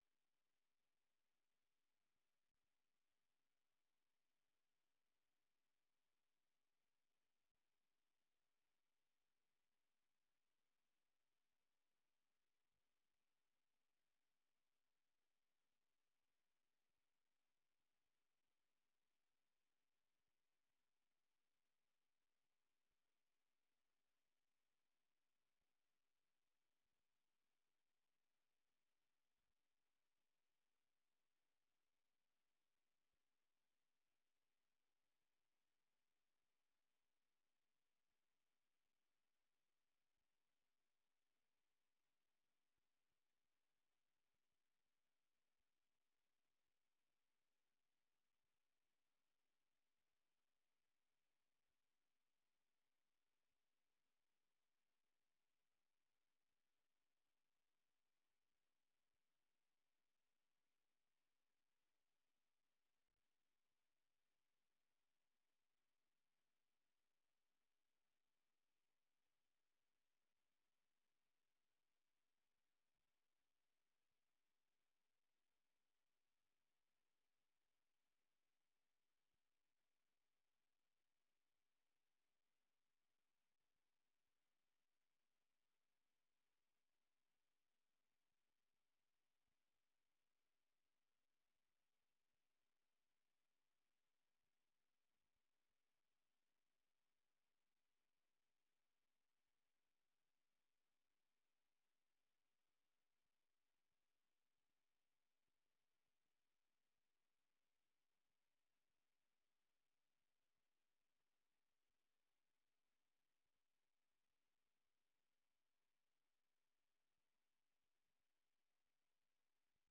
Oordeelsvormende vergadering 18 januari 2024 19:30:00, Gemeente Dronten
Locatie: Raadzaal